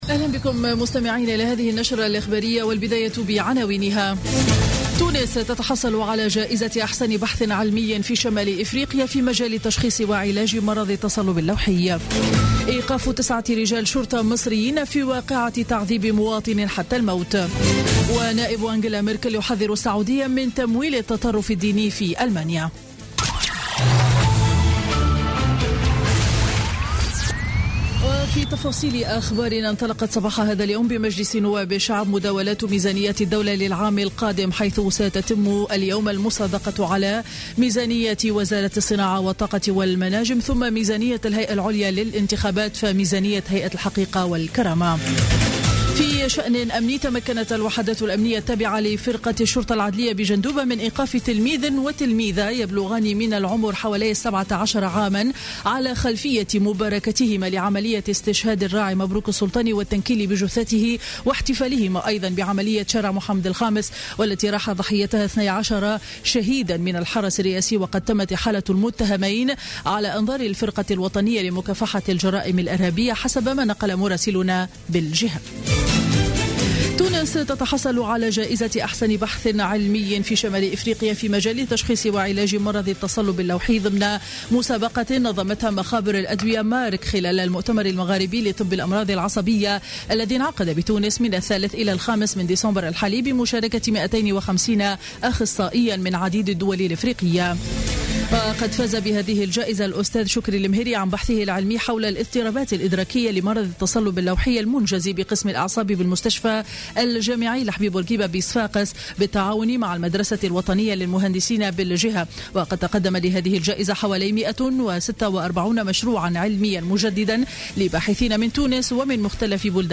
نشرة أخبار منتصف النهار ليوم الأحد 6 ديسمبر 2015